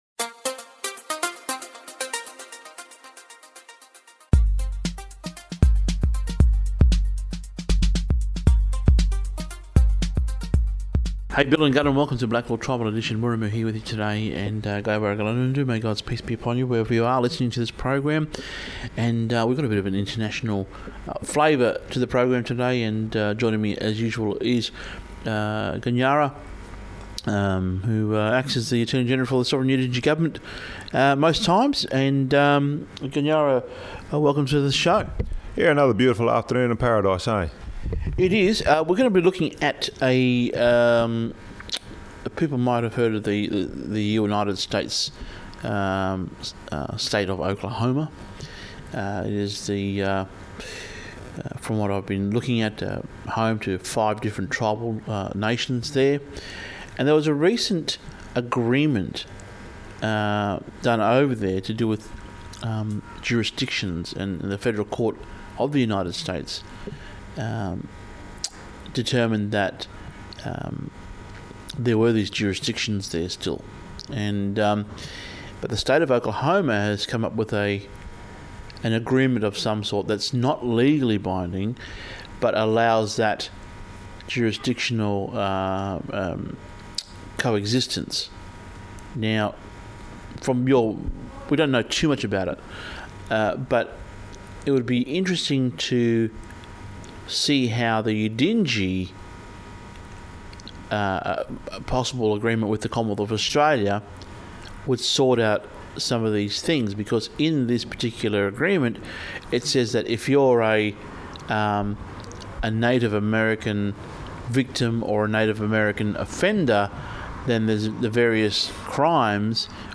We look at the state of Oklahoma’s admission that there are numerous tribal jurisdictions that still exist in that area today, but what now? Also on the program an interview with Princess Esmeralda of Belgium talking on the colonial wrongs of the past.